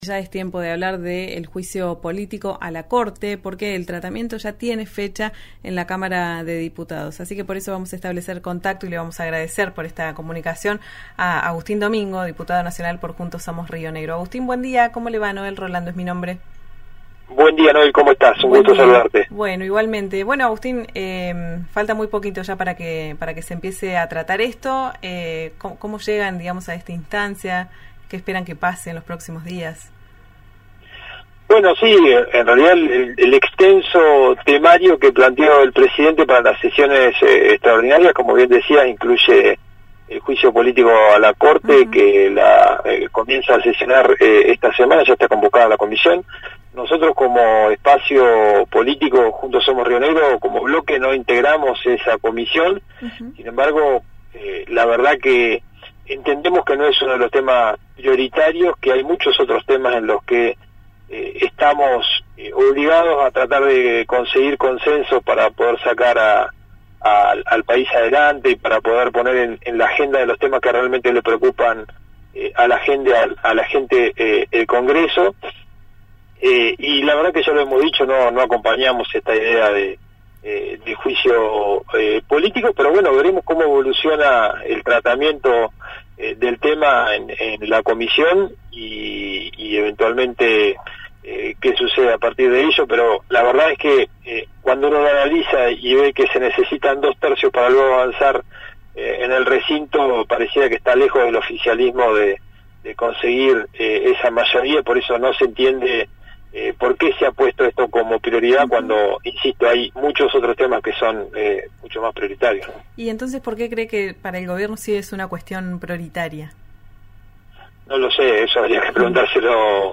Escuchá a Agustín Domingo, diputado nacional de Juntos Somos Río Negro, en «Ya Es Tiempo» por RÍO NEGRO RADIO